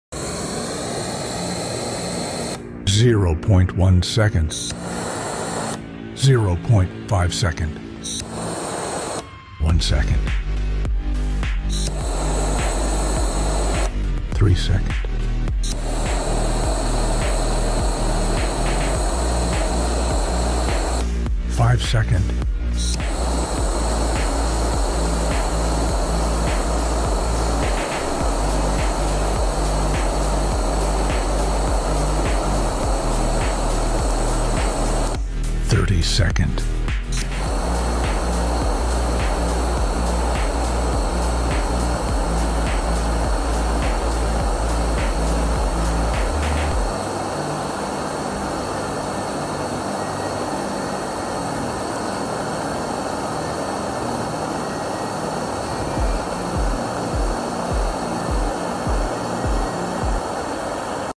Powerful Lighter vs Paper Cup sound effects free download
Powerful Lighter vs Paper Cup With Water